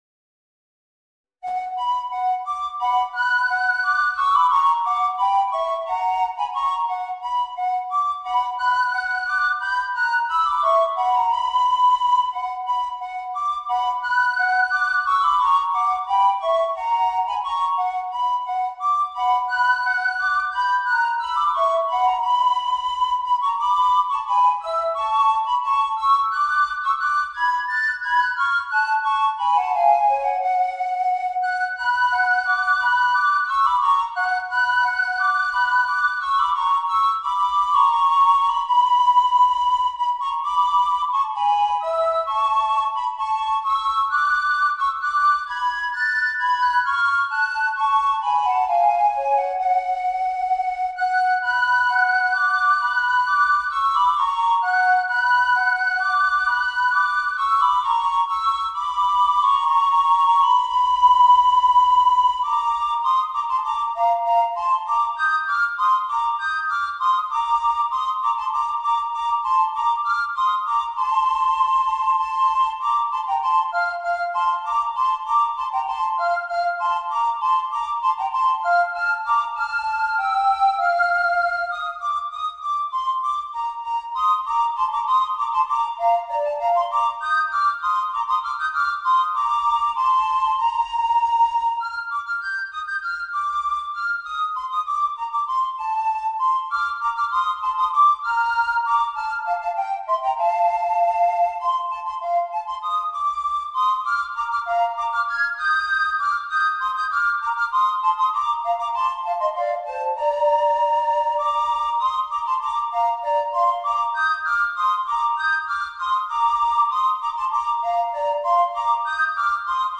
ソプラノリコーダー二重奏